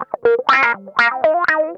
ITCH LICK 5.wav